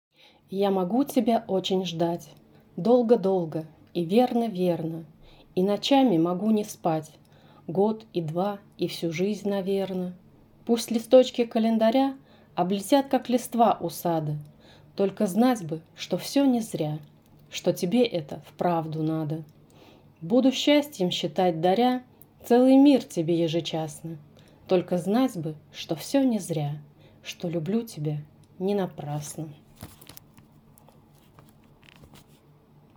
Я старалась с выражением прочитать